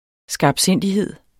Udtale [ sgɑbˈsenˀdiˌheðˀ ]